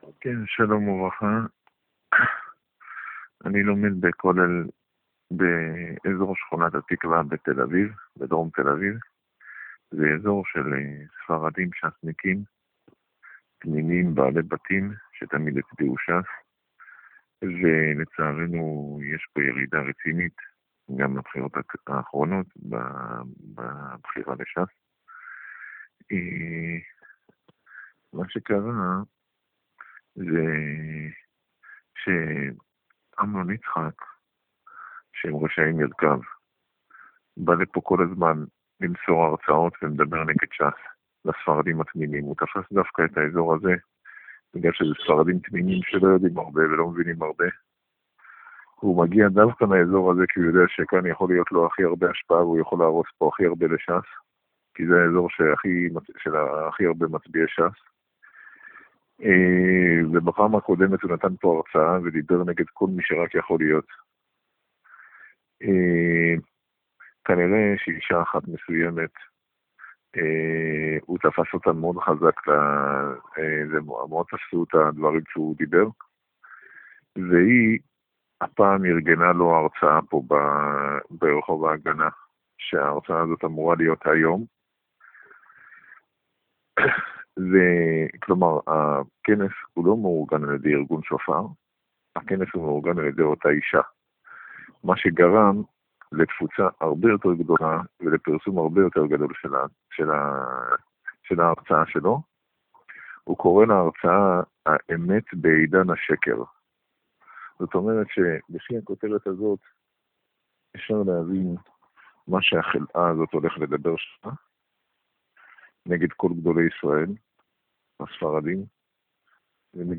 הודעה קודמת מ'קול סיני' שהושמעה קרוב למועד ההרצאה בתל אביב